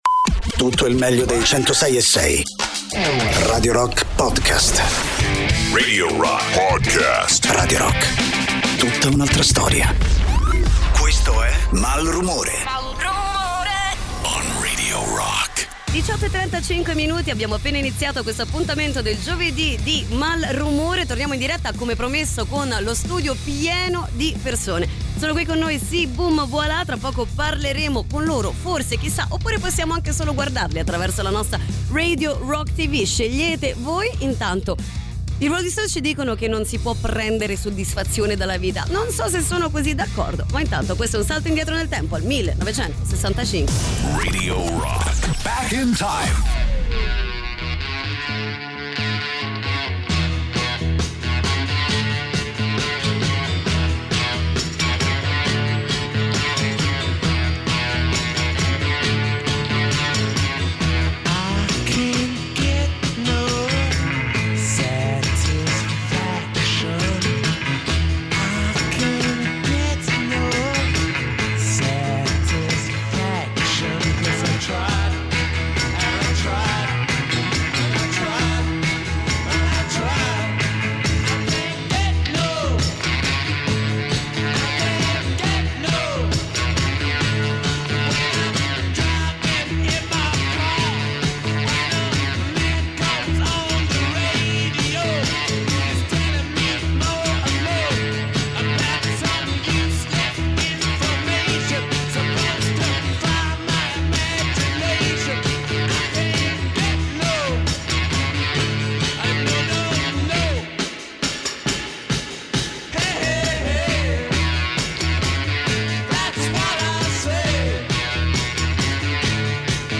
Intervista: SI! BOOM! VOILÀ!